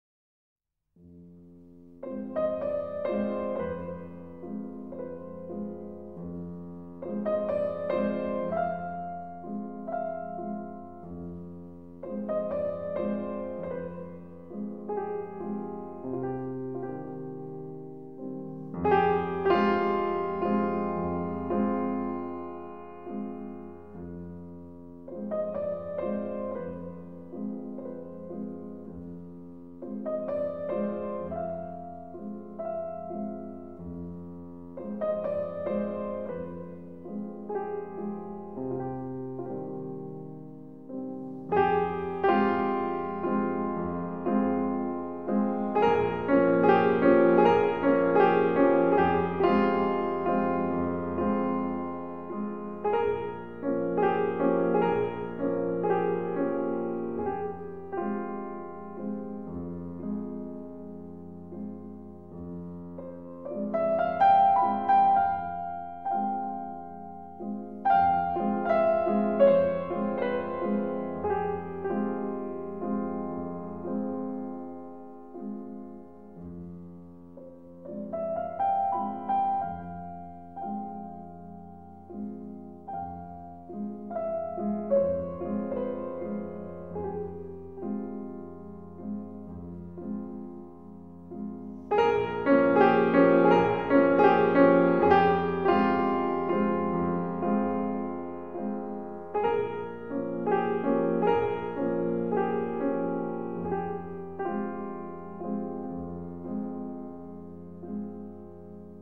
Estremecedora actuación de Maurice Ronet, gran fotografía de Ghislain Cloquet y conmovedora partitura para piano de Erik Satie.